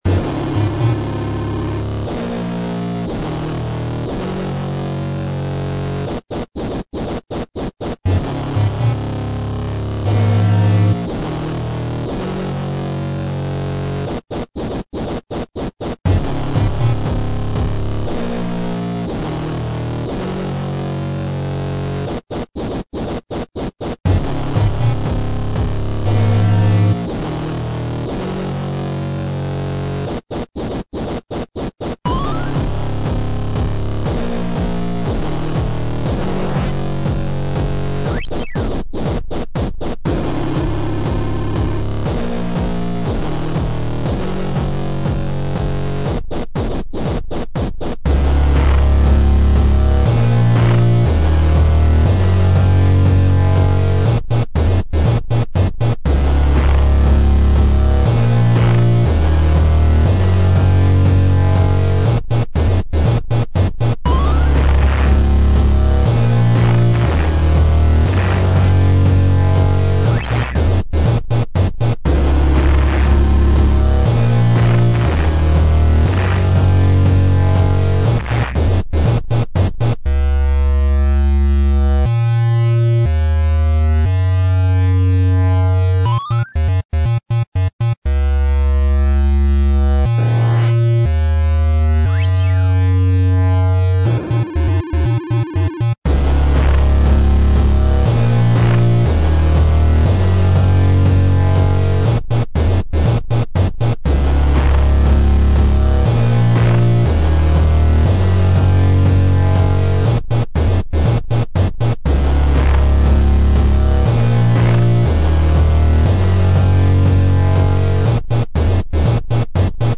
I think we should make a tread where we can share funny music that shait we made.I'll post the first one.
They fit on YouTube Poop background musics.